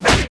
空手击中zth070511.wav
通用动作/01人物/03武术动作类/空手击中zth070511.wav